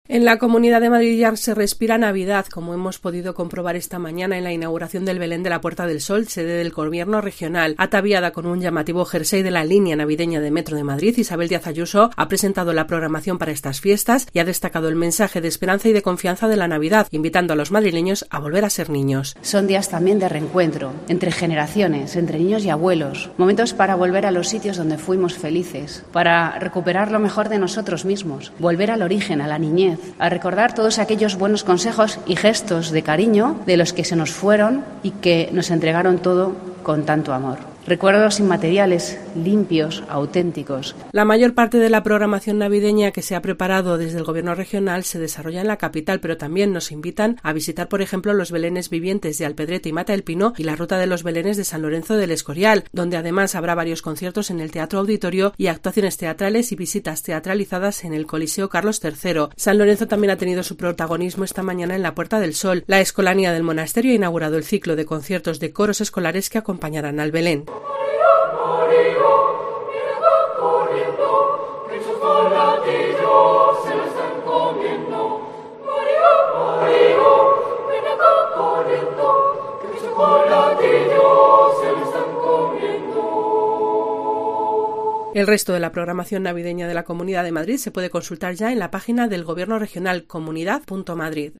Su concierto de villancicos ha puesto el colofón a la inauguración del Belén de la Puerta del Sol y la presentación de actividades por parte de la presidenta Díaz Ayuso